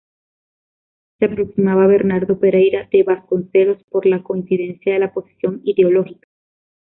coin‧ci‧den‧cia
/koinθiˈdenθja/